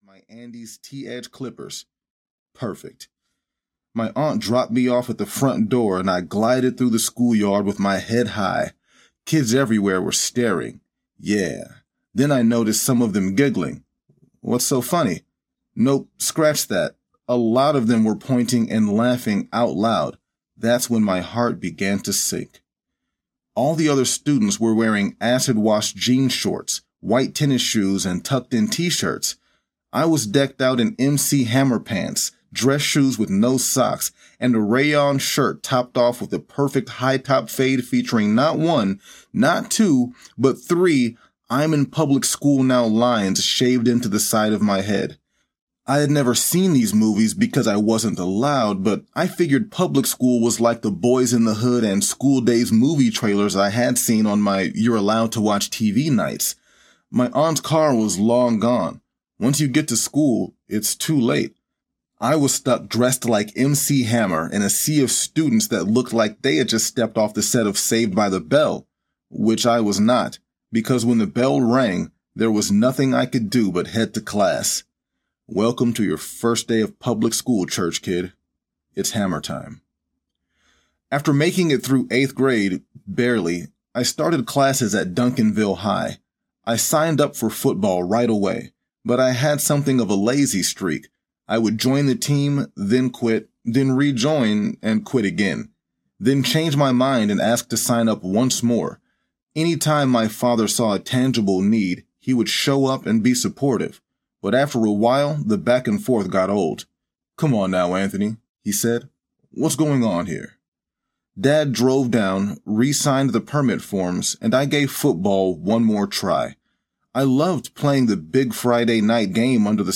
Unexpected Places Audiobook
Narrator
8.28 Hrs. – Unabridged